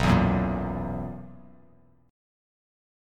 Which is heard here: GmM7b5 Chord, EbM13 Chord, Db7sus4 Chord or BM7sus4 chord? BM7sus4 chord